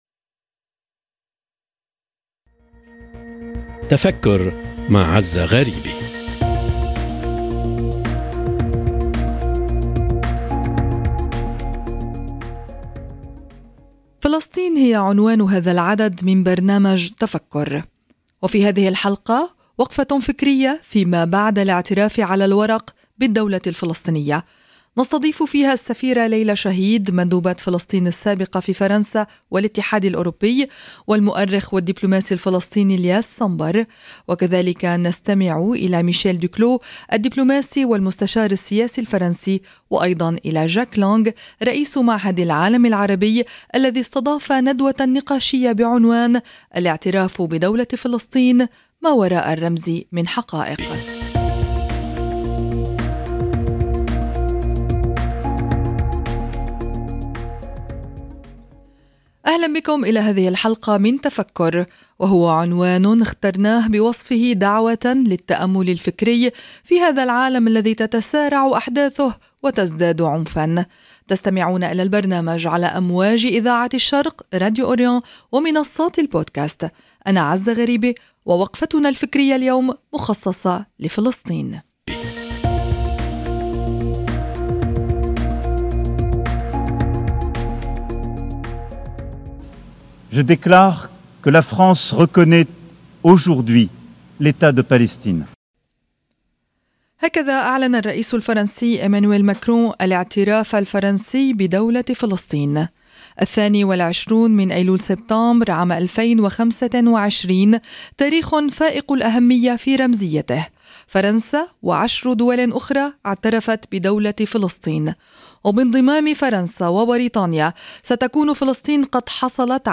فلسطين عنوان هذا العدد من برنامج «تفكّر». وقفة فكرية في ما بعد الاعتراف «على الورق» بالدولة الفلسطينية، مع السفيرة ليلى شهيد، المندوبة السابقة لفلسطين في فرنسا والاتحاد الأوروبي، والمؤرخ والدبلوماسي إلياس صنبر، وميشيل دوكلو الدبلوماسي والمستشار السياسي الفرنسي، وجاك لانغ رئيس معهد العالم العربي.
فما وزن هذه الاعترافات دولياً؟ هذه الأسئلة وغيرها يجيب عليها ضيوف الحلقة الأولى من برنامج تفكر.